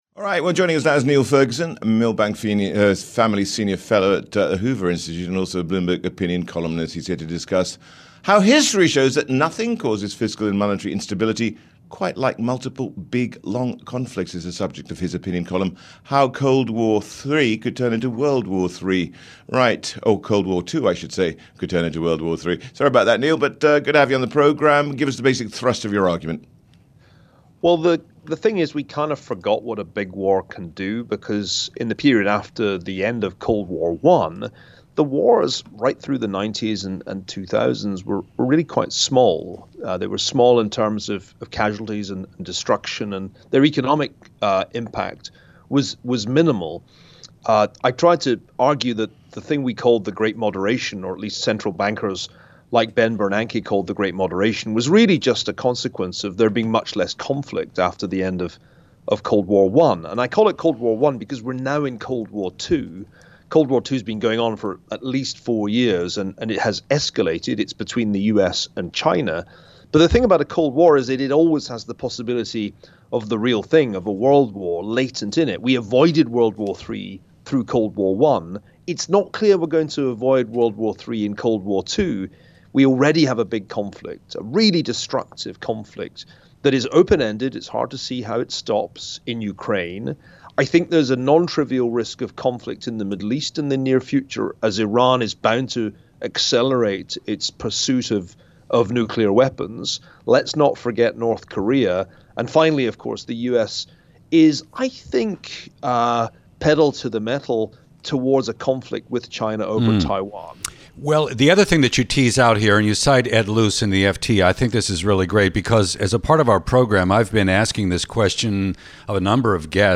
Niall Ferguson on Fiscal and Monetary Instability and Conflicts (Radio)